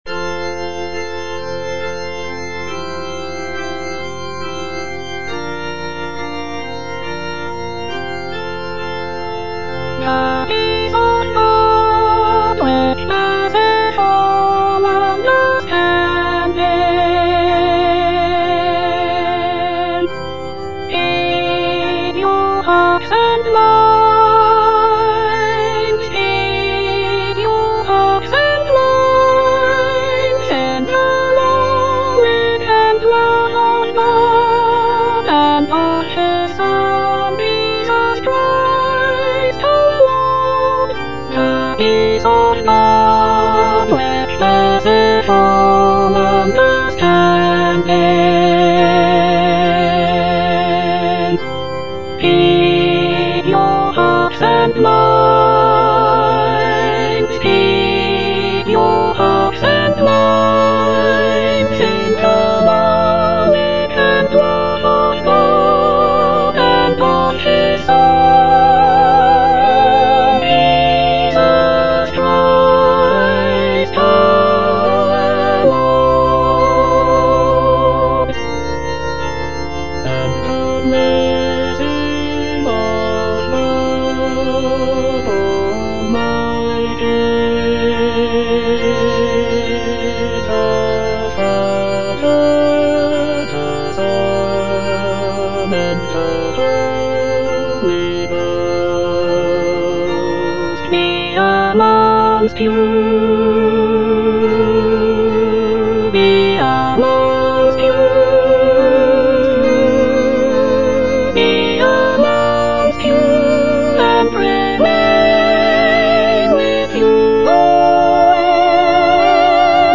Soprano (Emphasised voice and other voices)